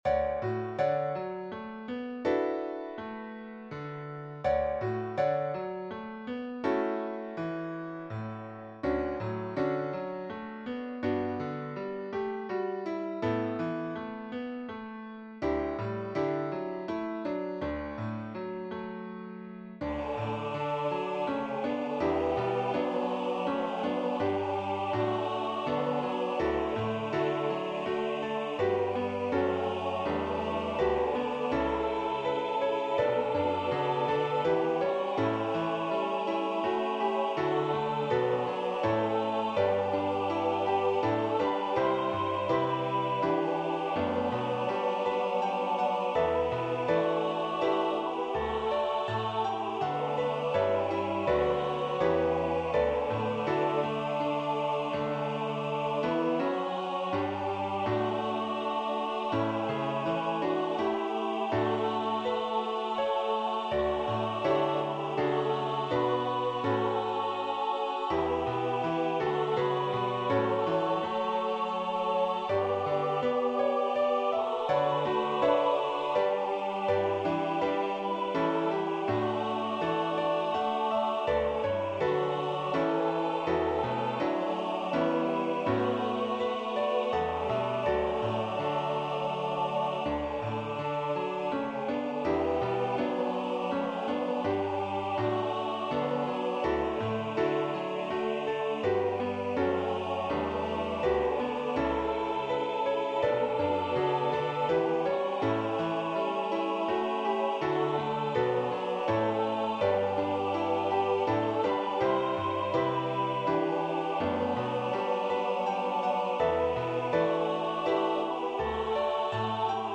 The intro creates the journey of the donkey.
Voicing/Instrumentation: SAB